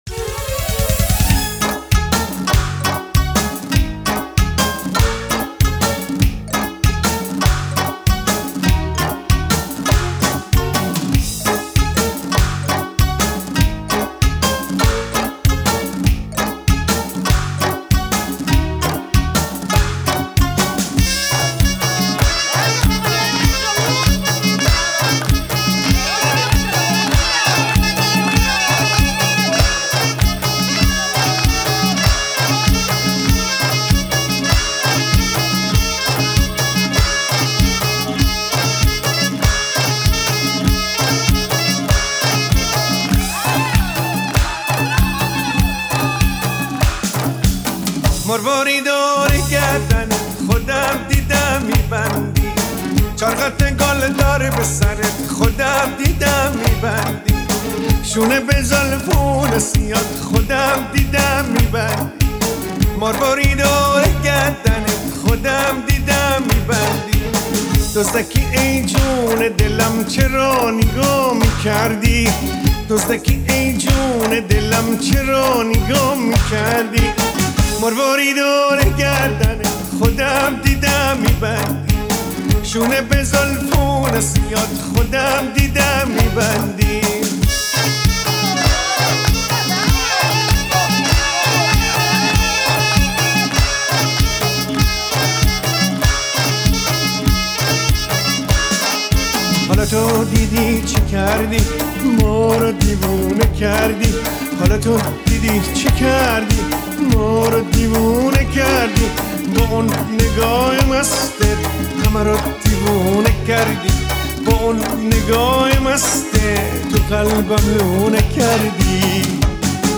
• هماهنگی لرزش شانه‌ها با ریزه‌کاری‌های سازهای کوبه‌ای